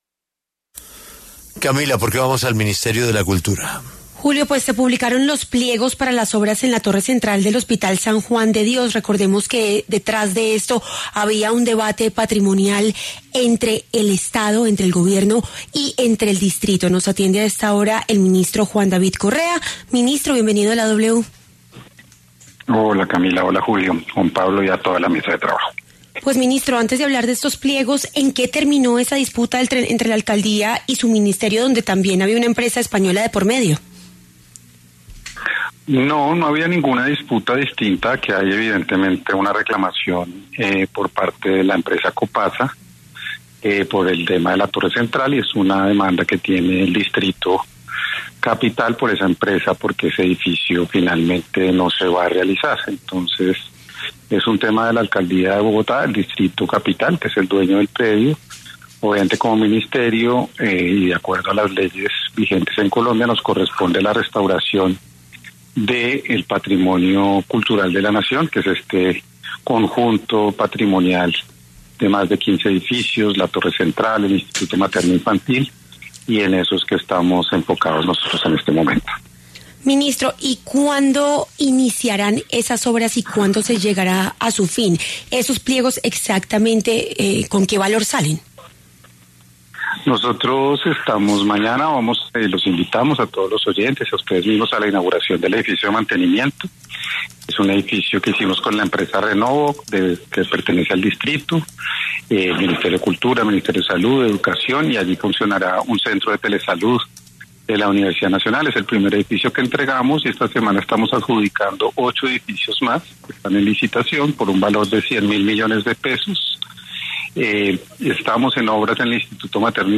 Durante una entrevista, el funcionario aclaró dudas sobre los proyectos en marcha y explicó el alcance de las obras, que se realizarán en coordinación con otras entidades gubernamentales.